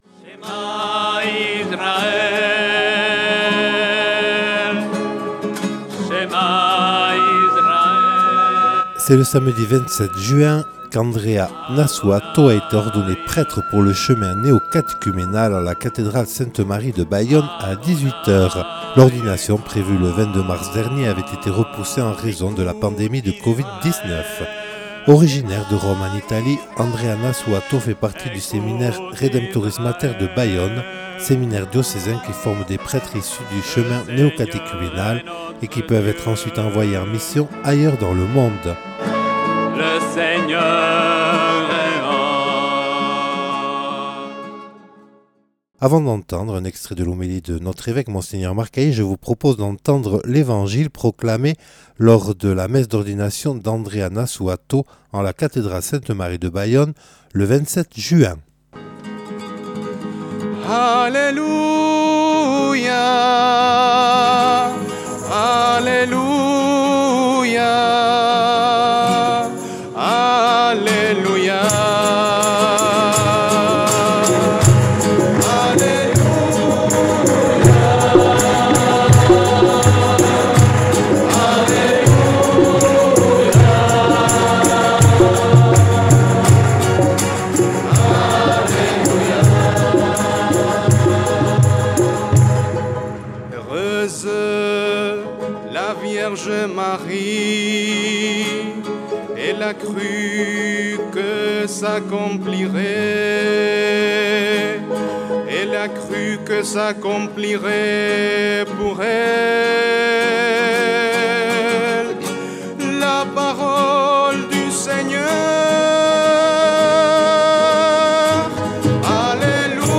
Revivre la célébration.
Interviews et reportages